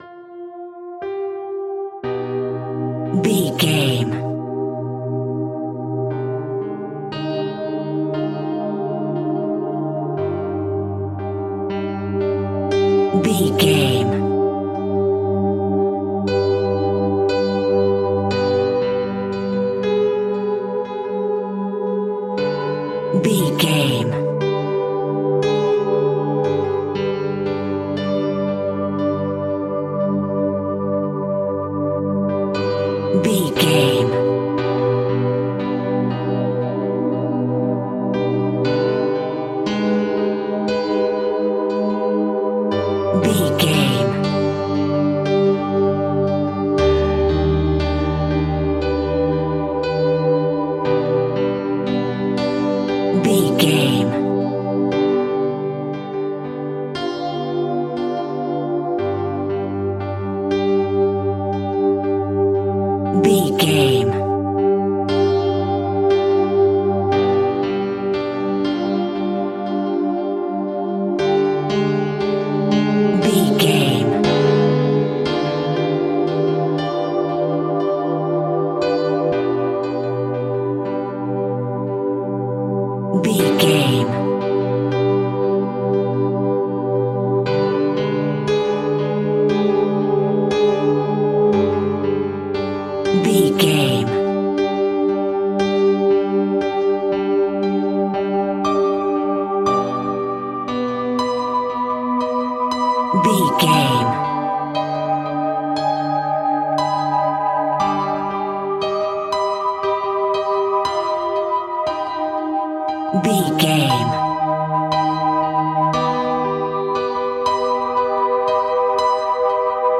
Diminished
ominous
suspense
eerie
piano
synthesiser
horror
Acoustic Piano